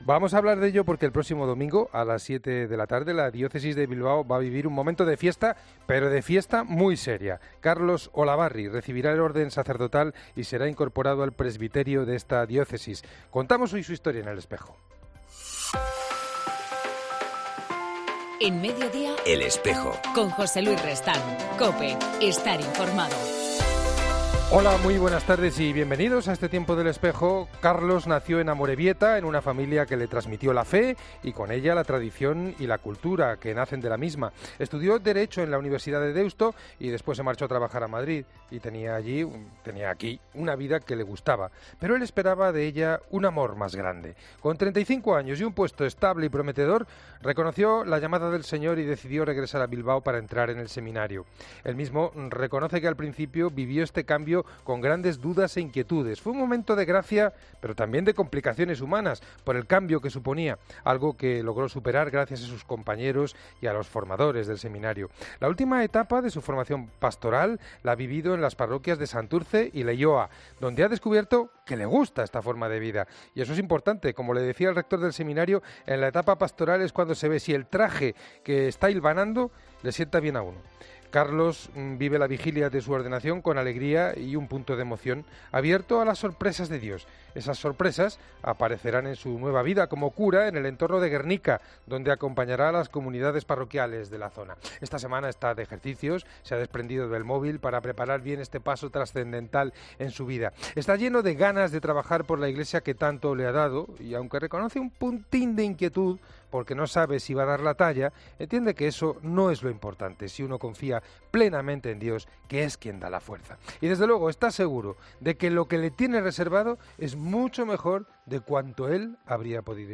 La entrevista será de los proyectos que la ONG CESAL tiene en marcha en El Salvador.